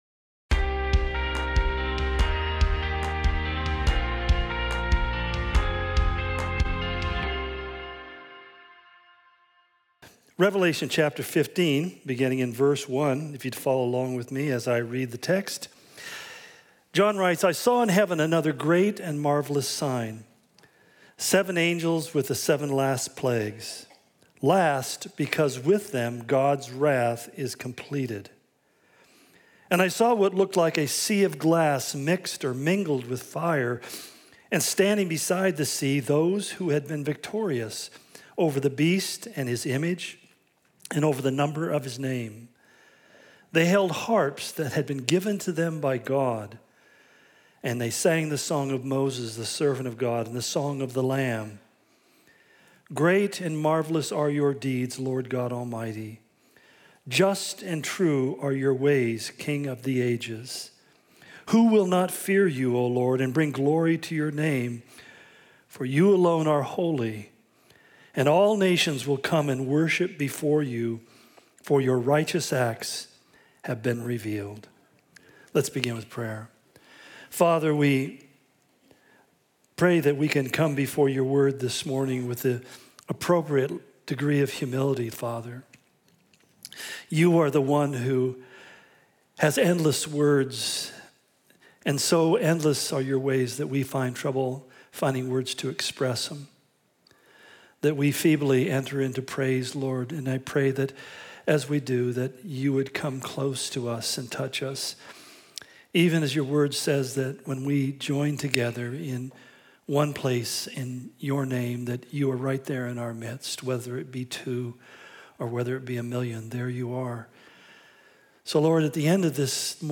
The Unveiling - Part 68 What We Worship Calvary Spokane Sermon Of The Week podcast